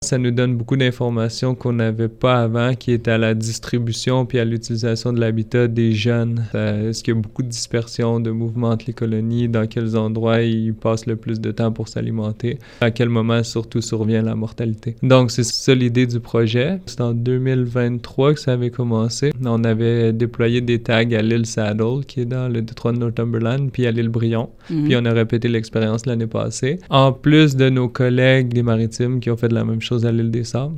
en visite aux studios de CFIM